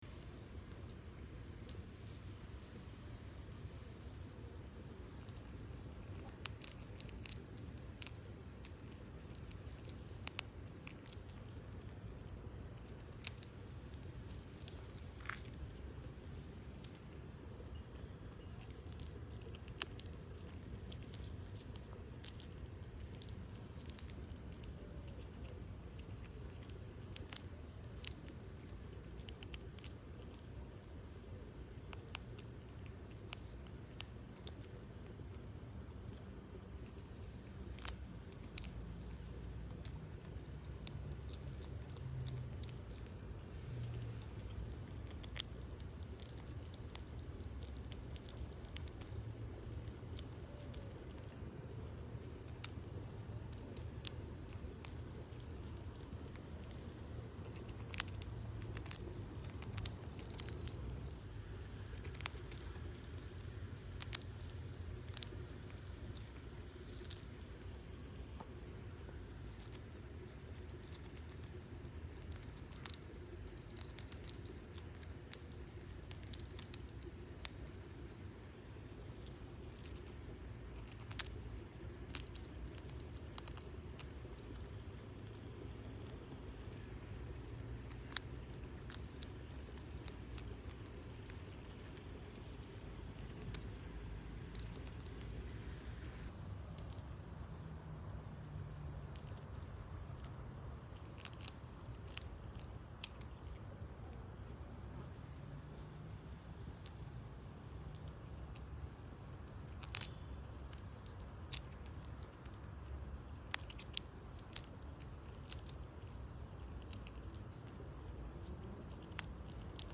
Скачать звук белки и слушать онлайн
Звуки издаваемые белками вы можете послушать у нас на сайте и скачать бесплатно.
belka-kushaet.mp3